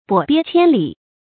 跛鱉千里 注音： ㄅㄛˇ ㄅㄧㄝ ㄑㄧㄢ ㄌㄧˇ 讀音讀法： 意思解釋： 跛腳的鱉只要半步也不停留，也能走千里。